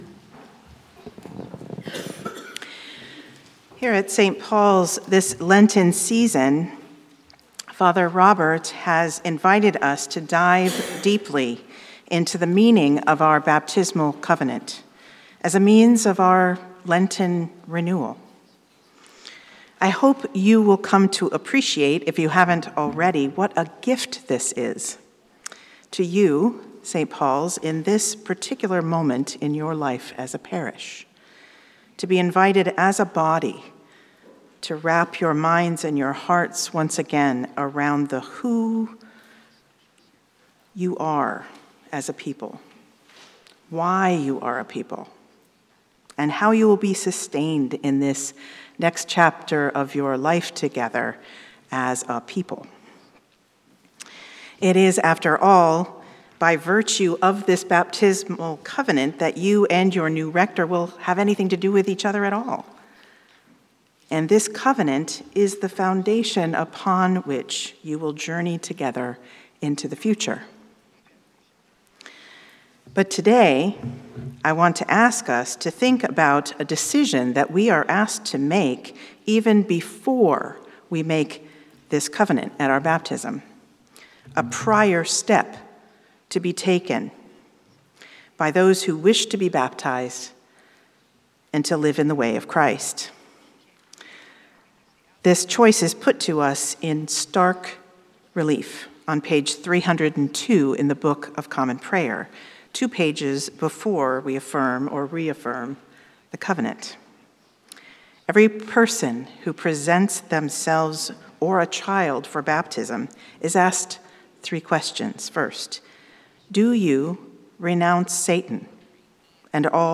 St-Pauls-HEII-9a-Homily-01Mar26.mp3